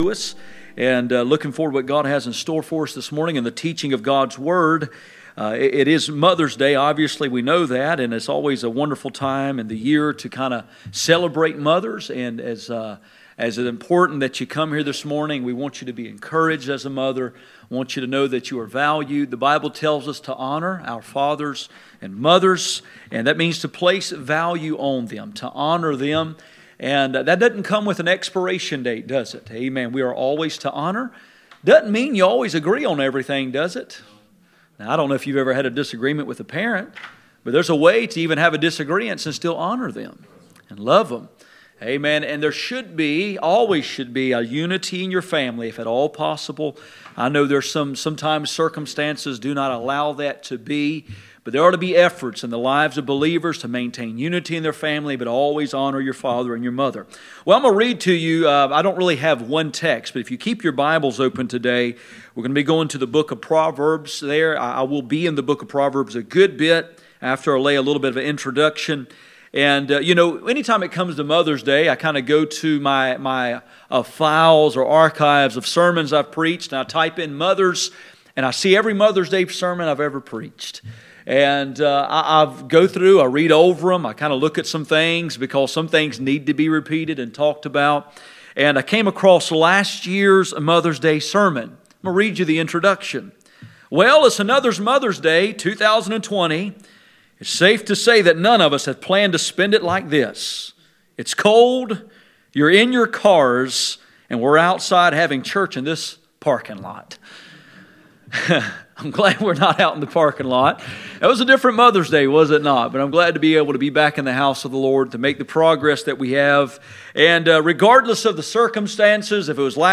Proverbs 1:8 Service Type: Sunday Morning %todo_render% « Are you Spiritual